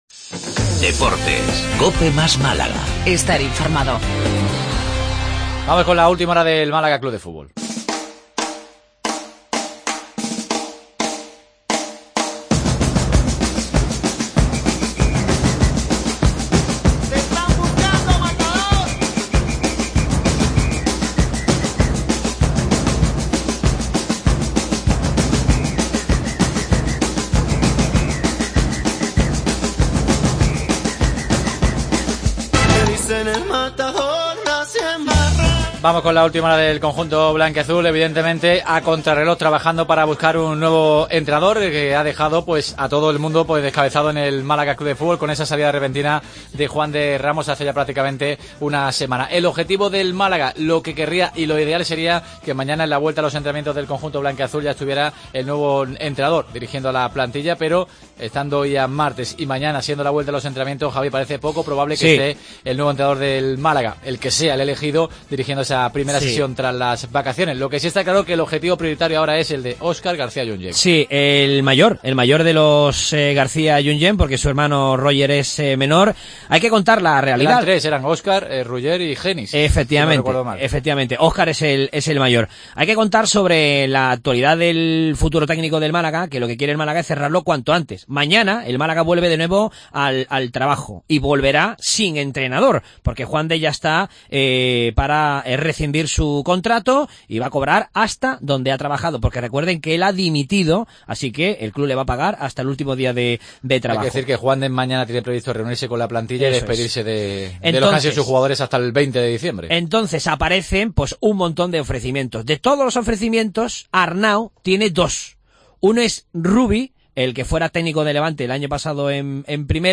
Entrevista a Jonathan Soriano (Red Bull Salzburgo)